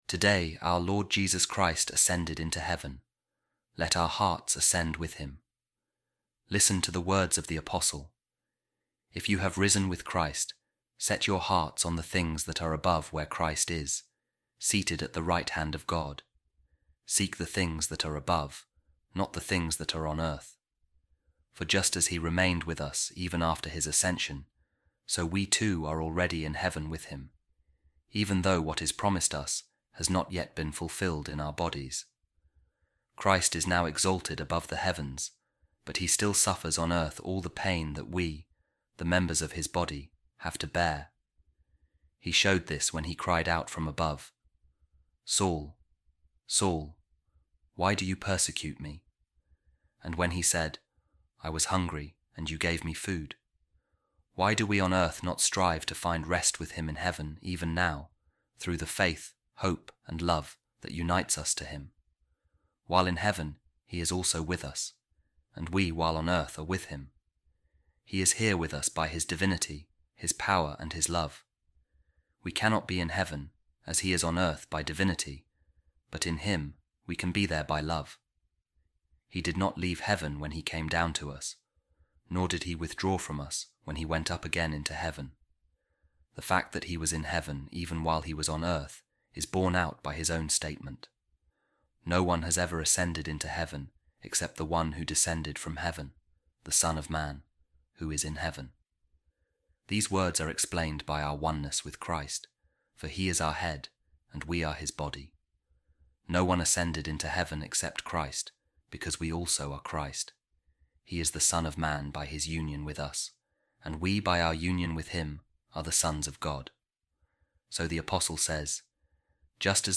Office Of Readings | Eastertide, Ascension | A Reading From The Sermons Of Saint Augustine | No-One Has Ascended Into Heaven But He Who Descended From Heaven